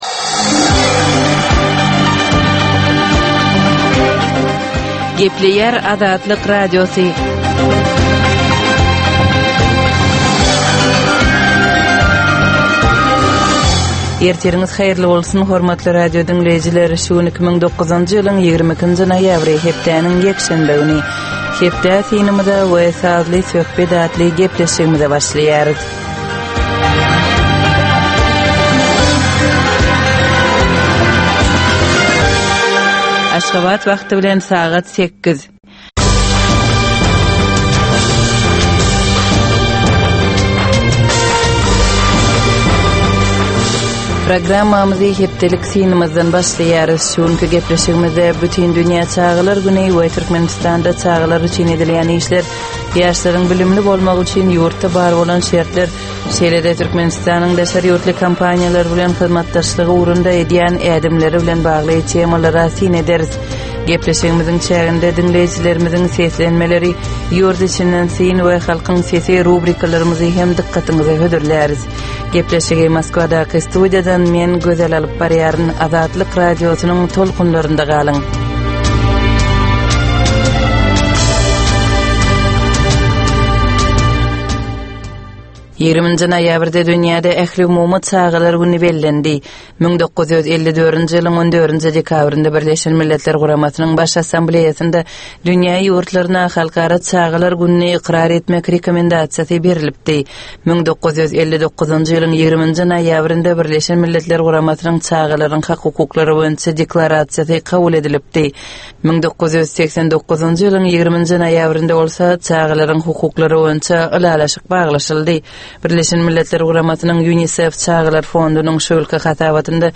Tutuş geçen bir hepdäniň dowamynda Türkmenistanda we halkara arenasynda bolup geçen möhüm wakalara syn. 30 minutlyk bu ýörite programmanyň dowamynda hepdäniň möhüm wakalary barada gysga synlar, analizler, makalalar, reportažlar, söhbetdeşlikler we kommentariýalar berilýär.